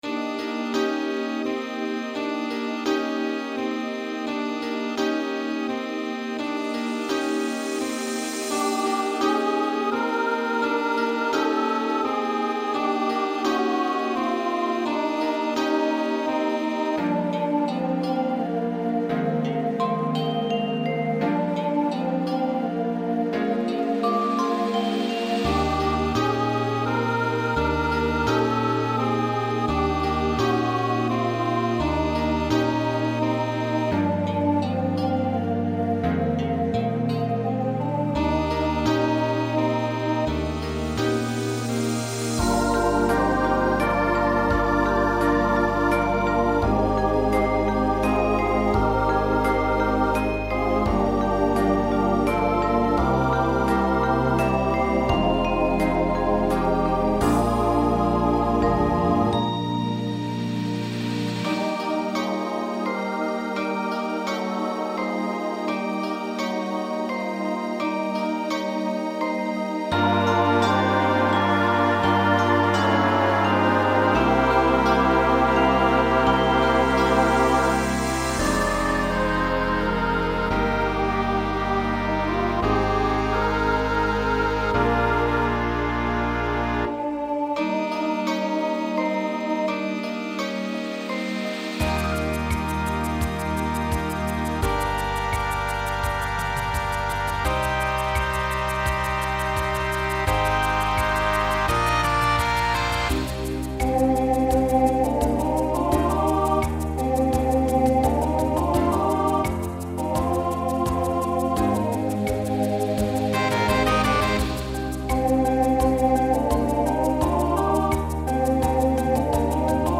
Voicing SATB Instrumental combo Genre Pop/Dance , Rock
Mid-tempo